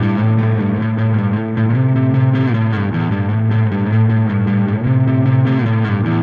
Spaced Out Knoll Electric Guitar 01.wav